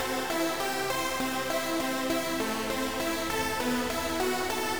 • Trance Background 8bit Texture.wav
Trance_Background_8bit_Texture__OH2.wav